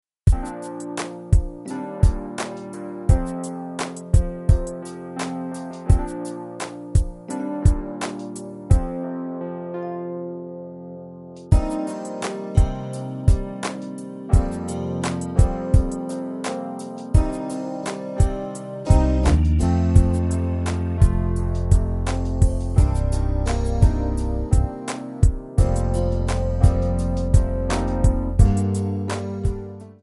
Backing track files: Pop (6706)
Buy With Backing Vocals.